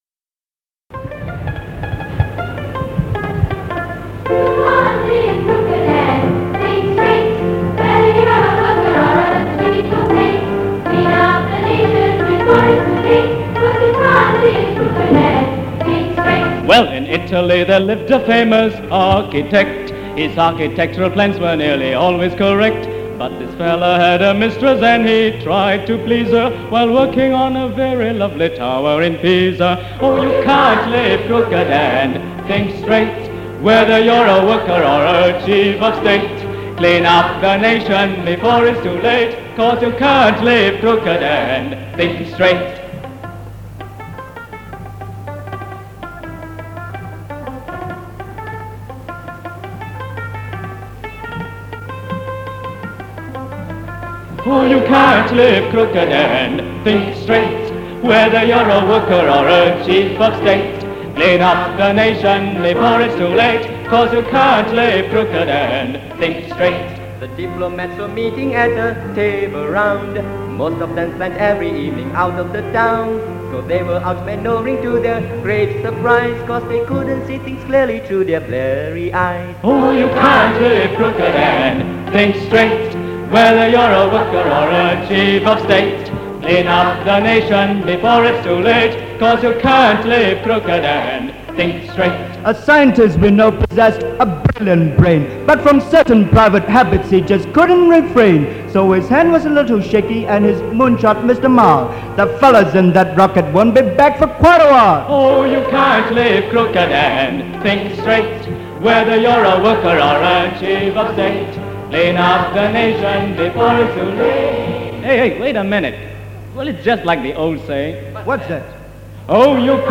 This is the final part of the Singout - a choral festival hosted by Methodist Girls' School (MGS) back in 1968. Together with the students of MGS, a few other schools also joined in to make up a 80-member strong choir.